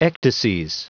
Prononciation du mot ecdyses en anglais (fichier audio)
Prononciation du mot : ecdyses